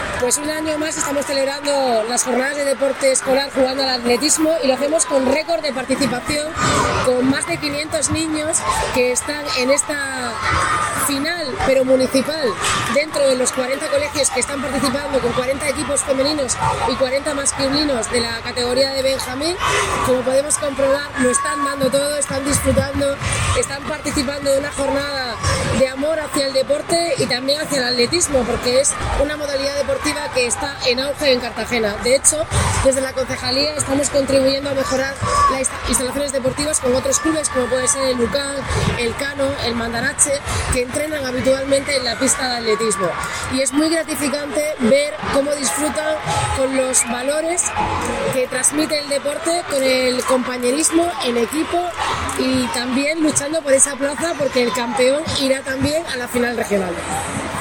Enlace a Declaraciones de la alcaldesa, Noelia Arroyo